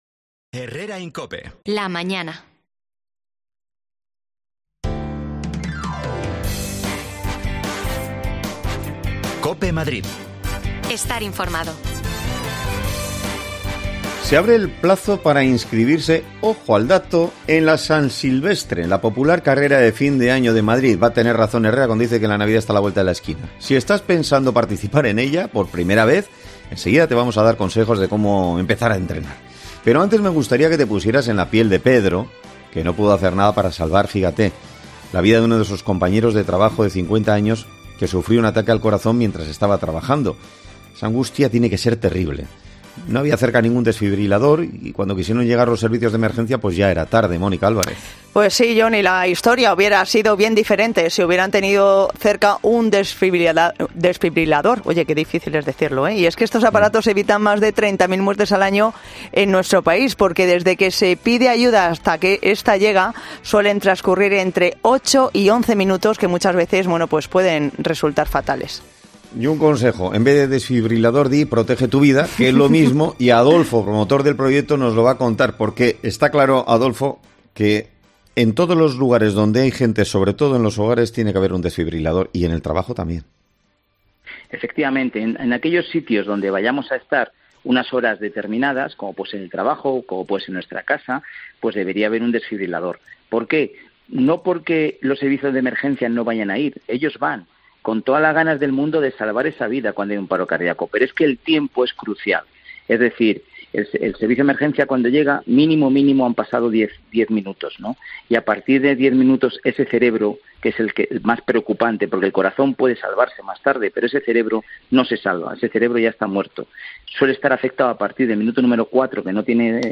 AUDIO: Se abre el plazo para correr la San Silvestre Vallecana. Si quieres correrla, escucha los consejos que nos da una entrenadora y corredora...
Las desconexiones locales de Madrid son espacios de 10 minutos de duración que se emiten en COPE , de lunes a viernes.